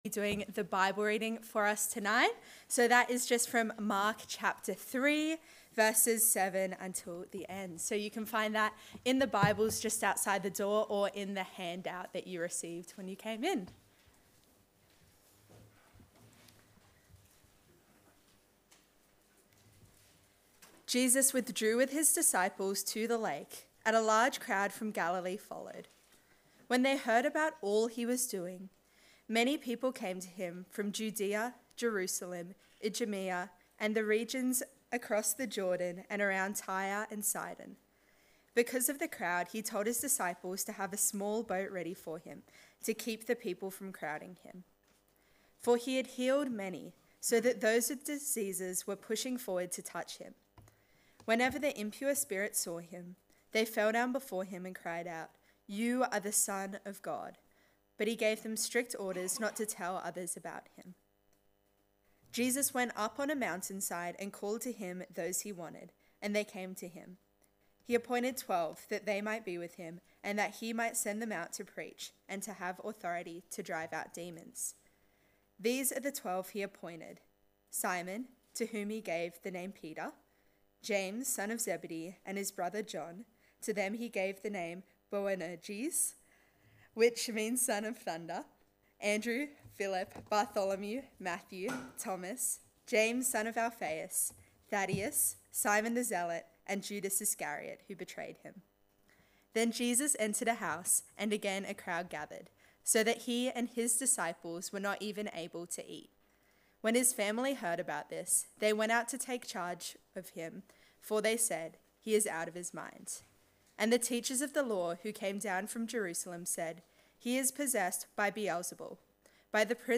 Series: The Suffering King Sermon: The King’s People Passage: Mark 3:7-35